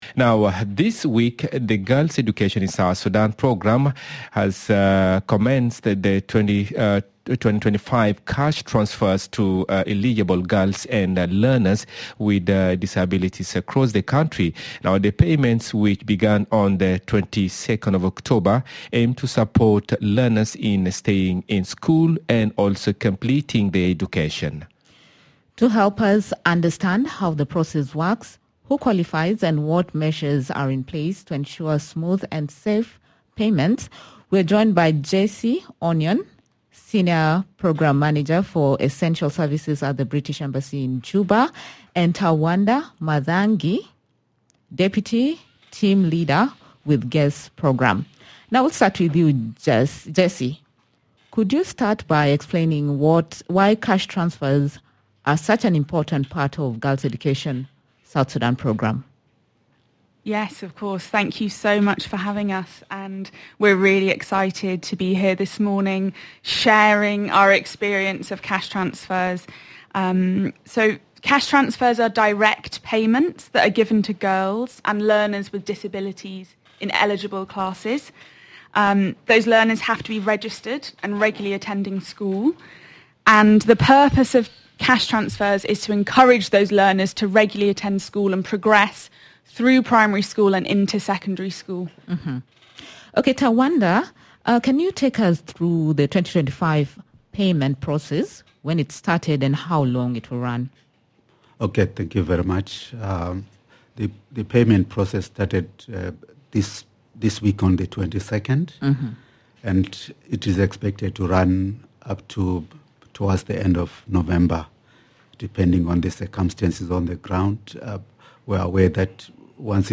The Miraya Breakfast show is in conversation with: